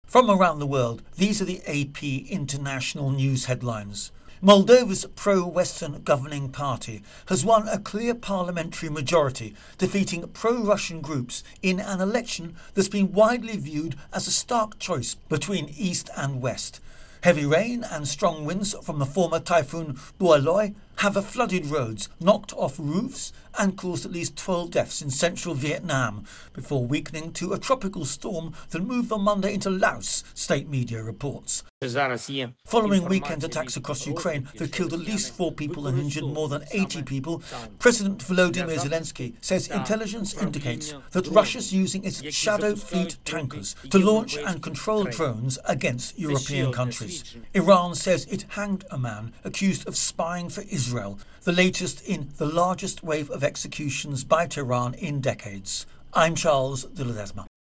The latest international news